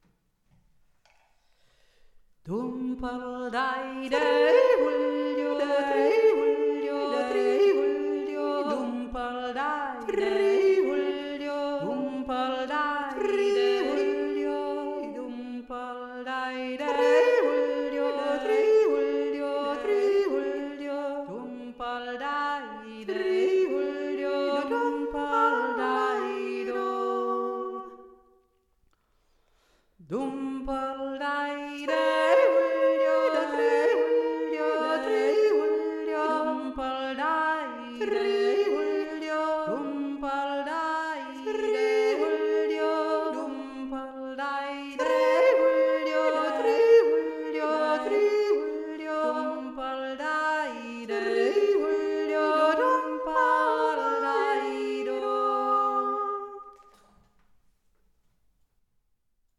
Wir tönten, improvisierten frei und dazwischen jodeten wir, inspiriert von den verschiedenen Räumen, dem wunderbaren Saal im Schloss, der Kirche in Pöllau und Pöllauberg und der St. Anna Kapelle... und dantürlich jodelten wir auch im Frei`n, sofern es das herbstlich frische Wetter zuließ: All das am Styrian Art Festival in Pöllau